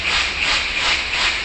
techage_steamengine.ogg